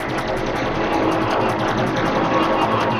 Index of /musicradar/rhythmic-inspiration-samples/80bpm
RI_DelayStack_80-03.wav